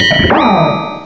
sovereignx/sound/direct_sound_samples/cries/escavalier.aif at 2f4dc1996ca5afdc9a8581b47a81b8aed510c3a8